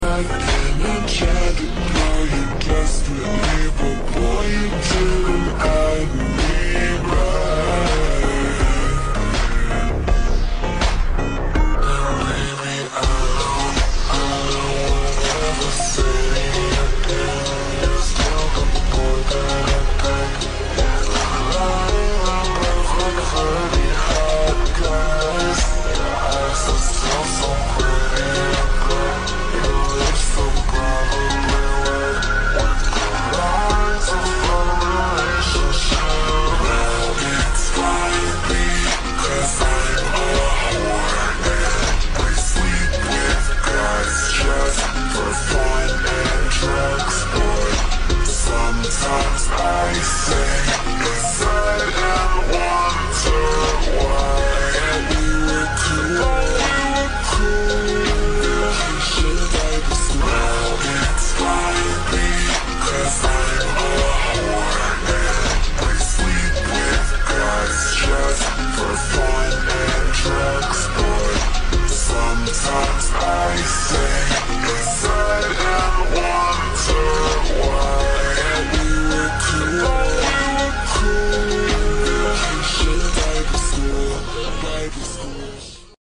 remake (Slowed)